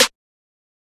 MB Snare (14).wav